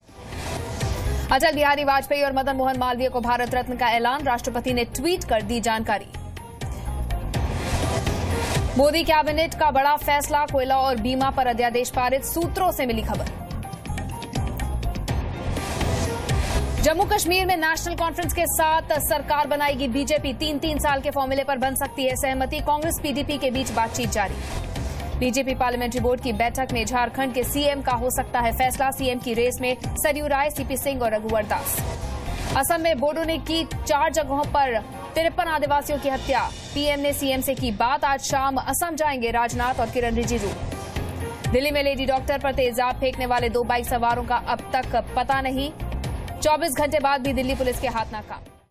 Top headlines of the day